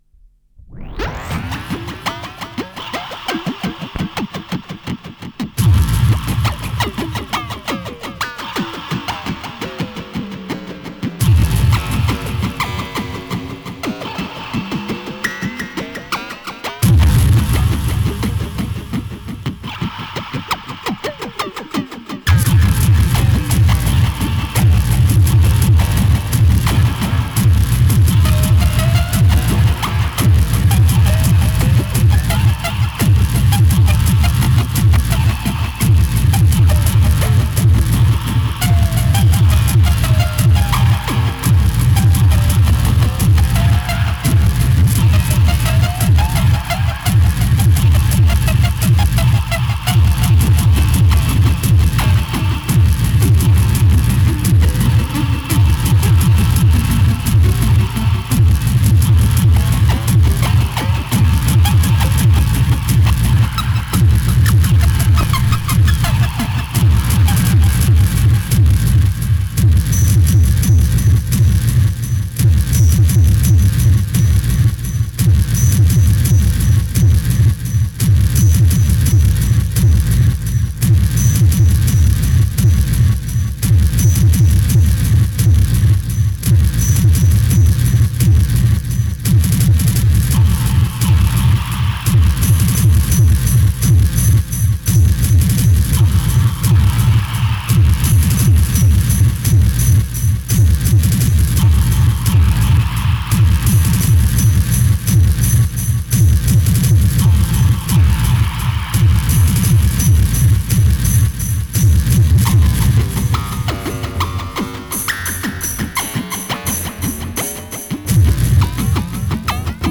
Voilà un mix un peu à l'arrache avec un son assez moyen pour fêter la fin de mes études.